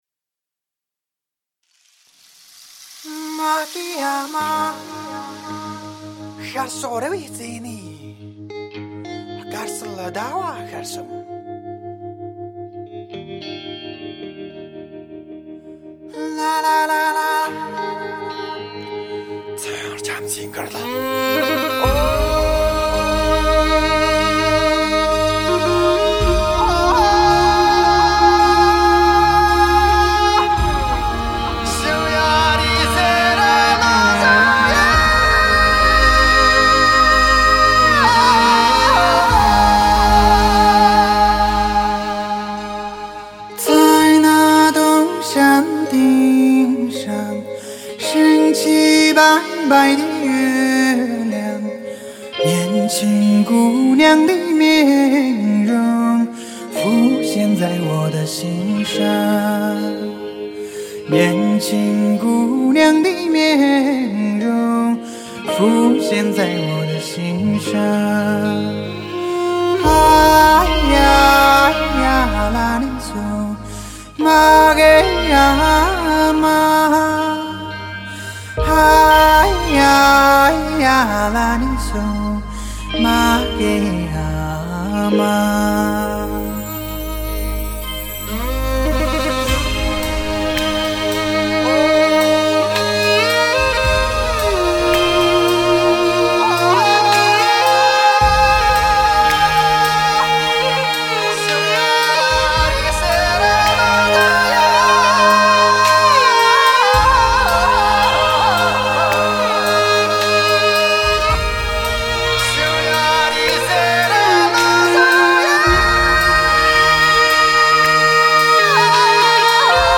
释放蒙藏的古朴神秘  糅合时尚多元的音乐元素
高亢辽阔  絮语萦回  诠释至情至性的高原情歌